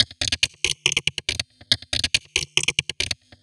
tx_perc_140_scrapey.wav